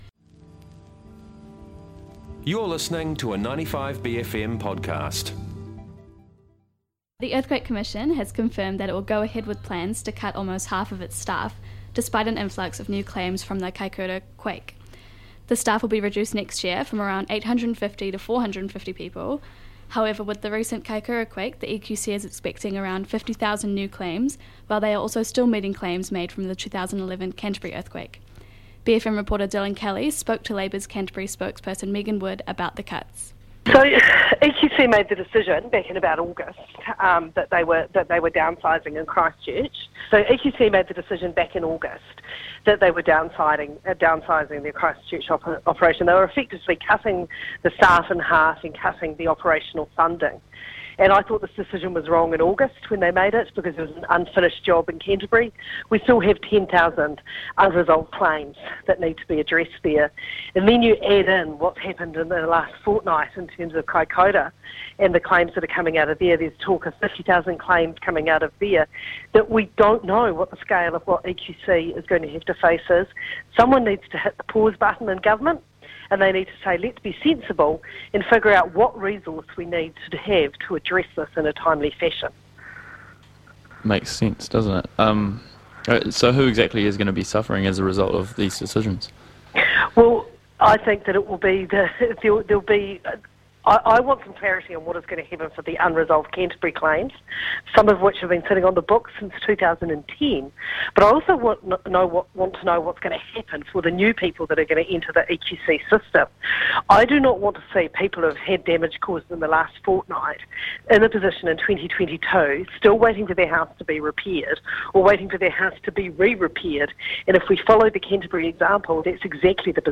speaks to Labour’s Canterbury spokesperson Megan Wood